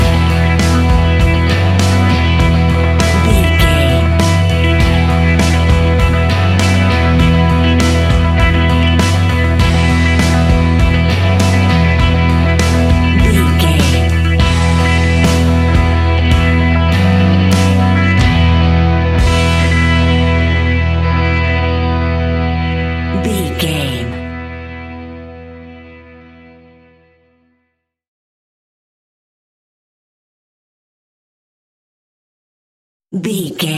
Ionian/Major
indie pop
energetic
uplifting
instrumentals
upbeat
groovy
guitars
bass
drums
piano
organ